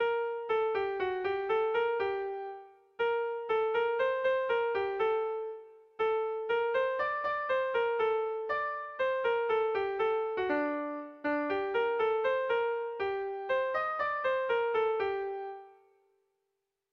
Bertso melodies - View details   To know more about this section
Erromantzea